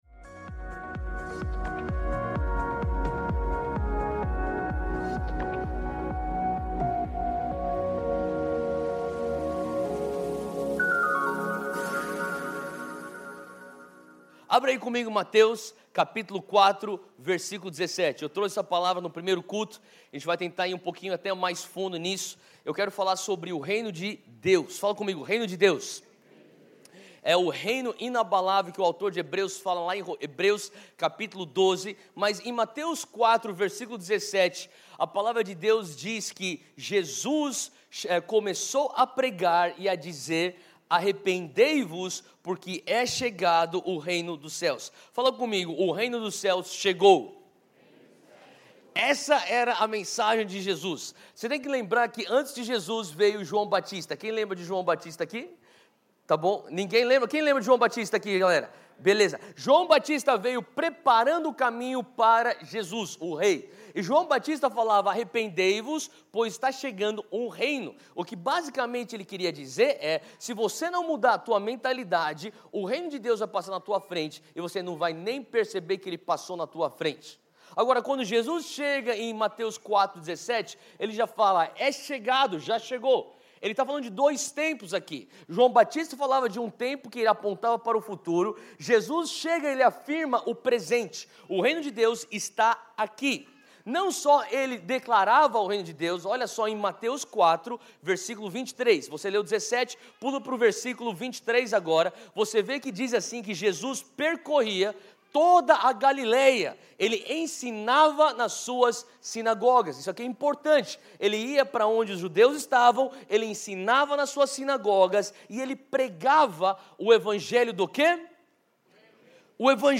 Mensagem realizada no dia 24/11/2018.